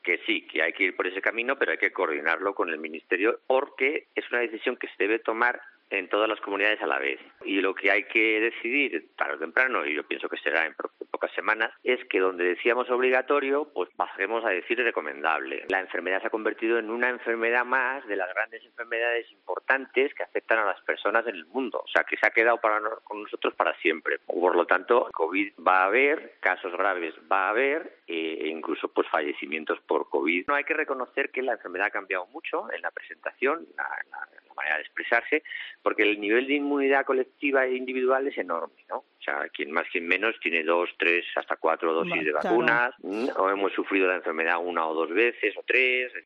epidemiólogo, explica si es posible el final de las mascarillas en hospitales